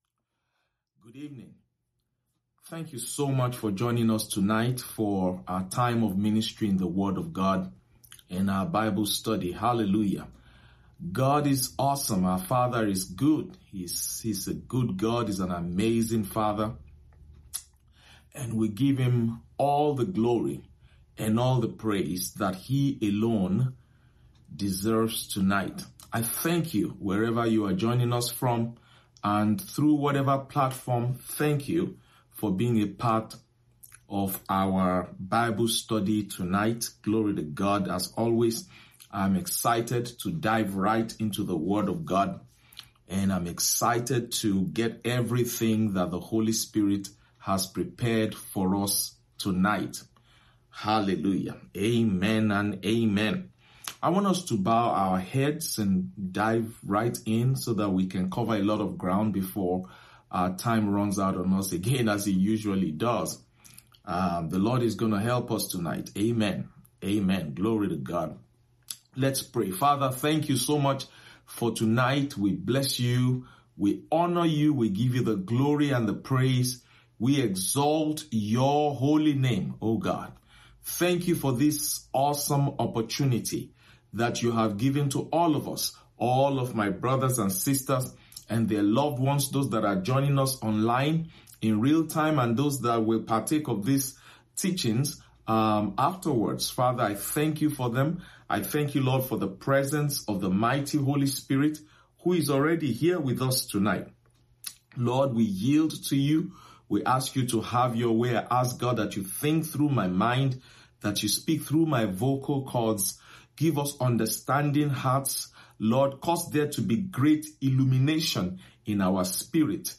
Sermons – Abundant Life International Church podcast